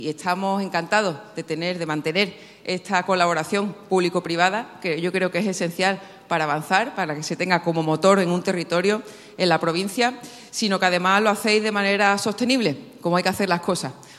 Almudena Martínez resalta en la presentación en Sevilla el compromiso de la Diputación con estos eventos a través de la colaboración público-privada